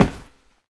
RunMetal8.ogg